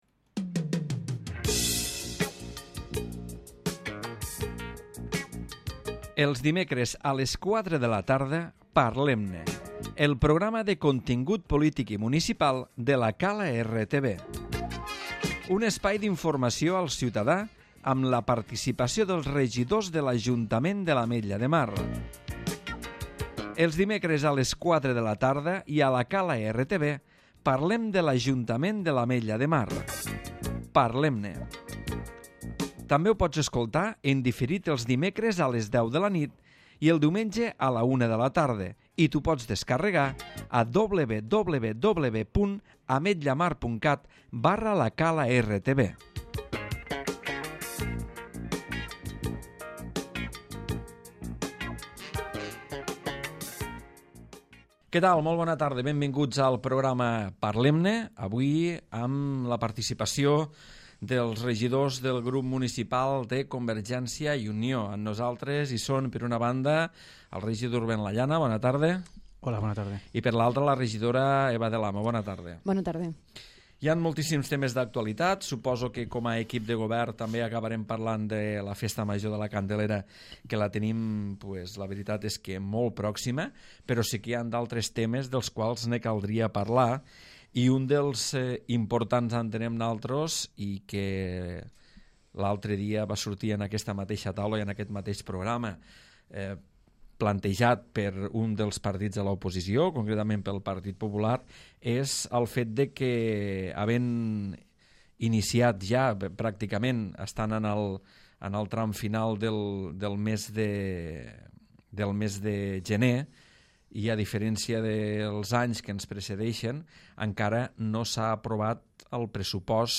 ruben Lallana i Eva del Amo, regidors del Grup Municipal de CiU analitzen l'actualitat municipal i la tasca de l'equip de govern.